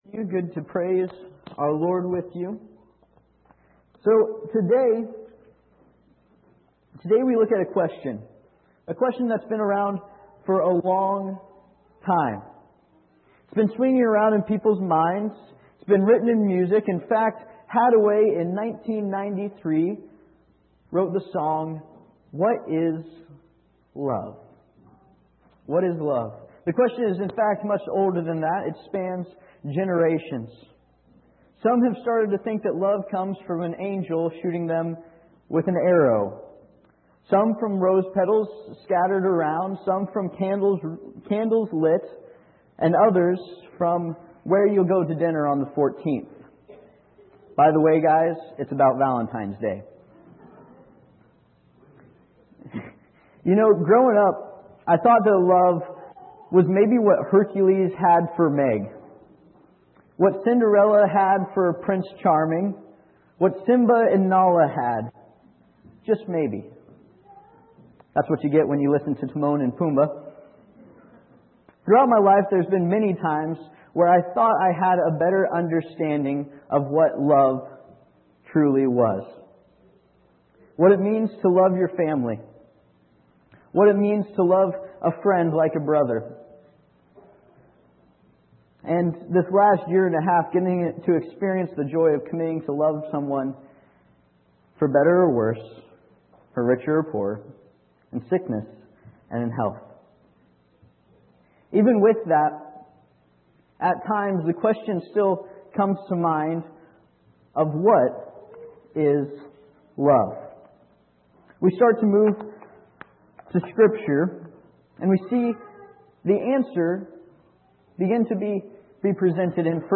Passage: 1Corinthians 13, Ephesians 4:14-16, Colossians 3:12-14, Luke 6:27 Service Type: Sunday Morning Topics: Love , Loving Others , Valentines « Made to Love Love is…